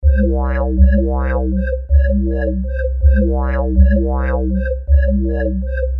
DRUMAND...160bpm
描述：刚做了一个短的鼓和贝斯循环。 每个循环的最后都有一个小尾巴，用于延迟褪色。